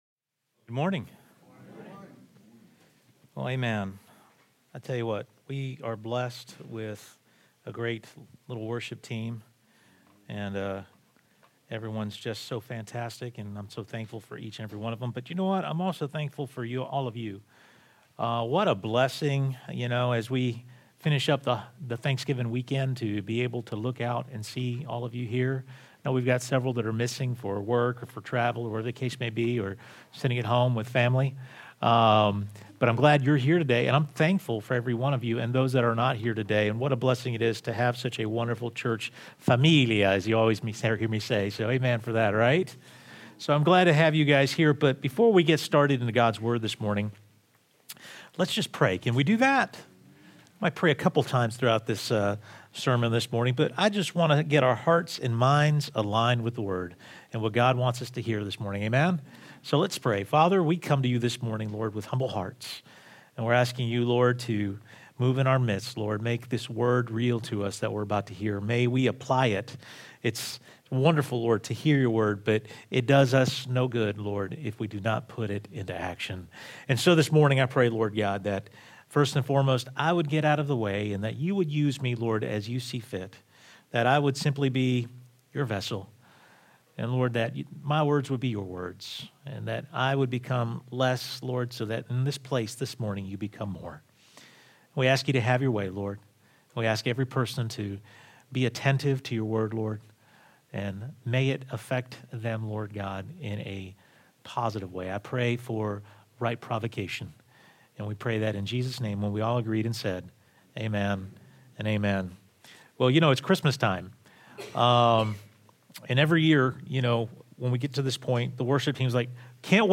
Join us for the first sermon in our Christmas series, "What's Your Focus?"